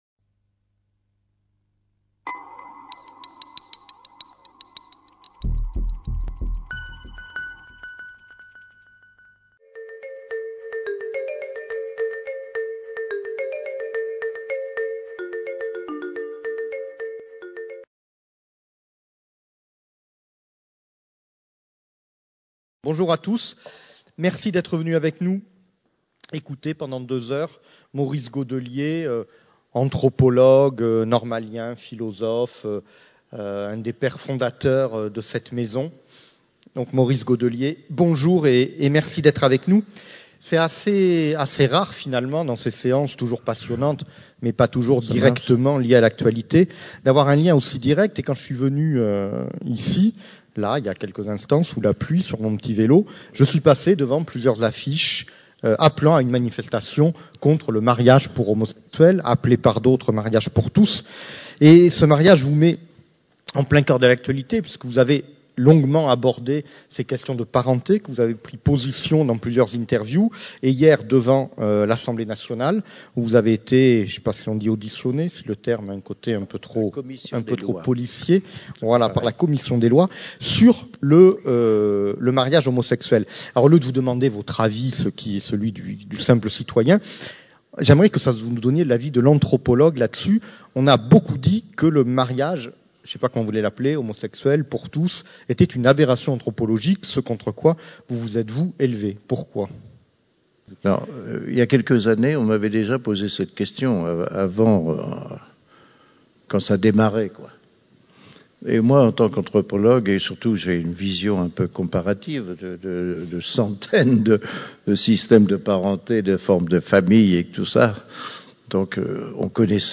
Rencontre avec Maurice Godelier, anthropologue océaniste